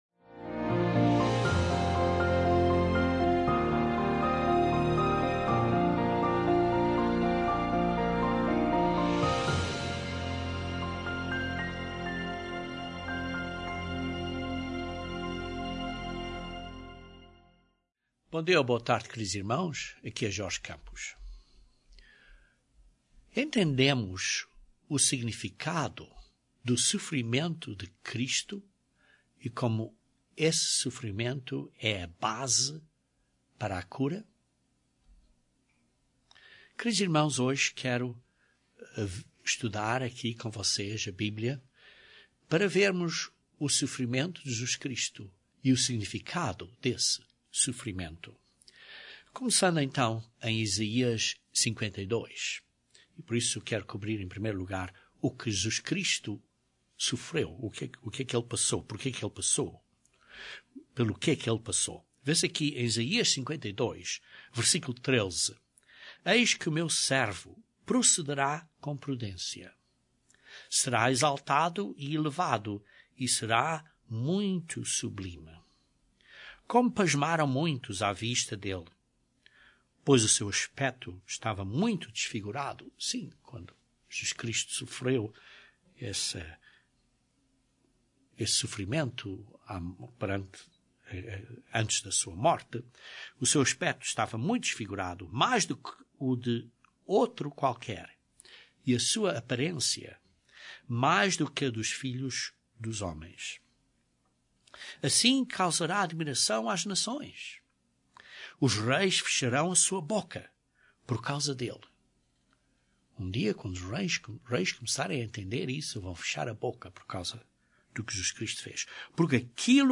Jesus Cristo pagou completamente o preço do pecado - sim a morte, mas também o preço do sofrimento que existe neste mundo por causa do pecado. Este sermão aborda em detalhe o significado do simbolismo do pão na cerimónia da Páscoa.